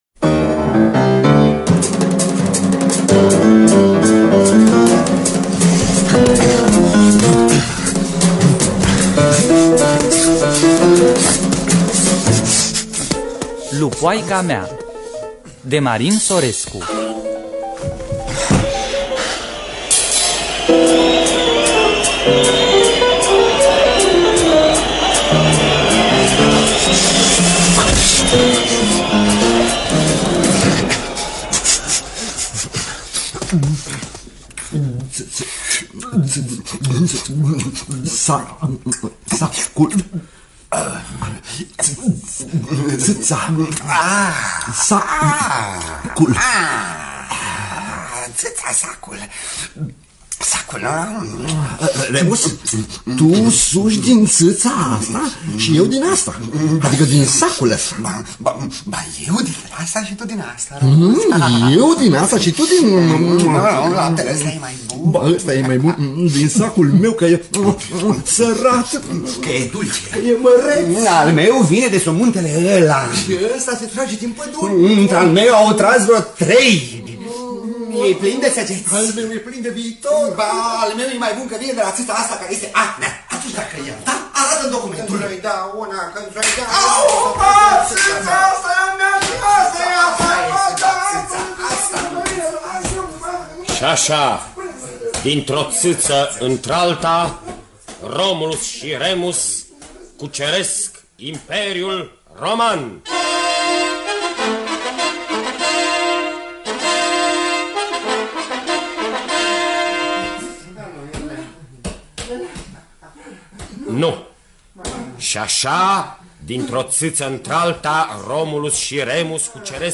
Efecte percuție